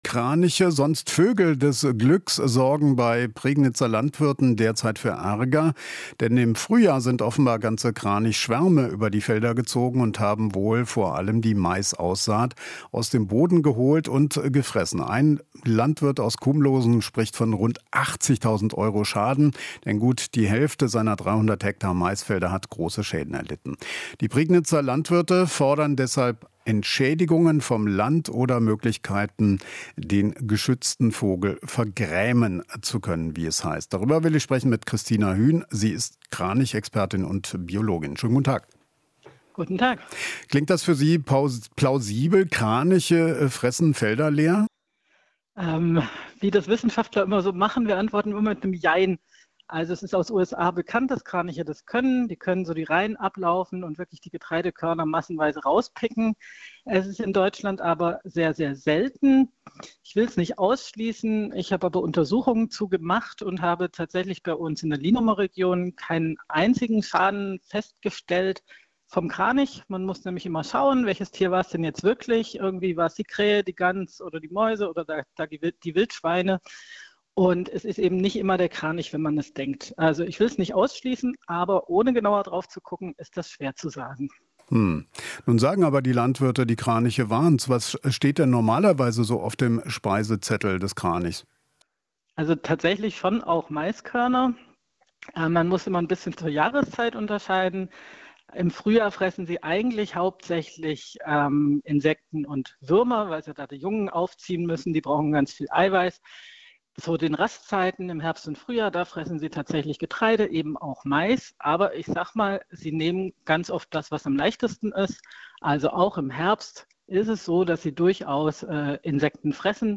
Interview - Kraniche in der Prignitz: Expertin rät zum "Umlenken"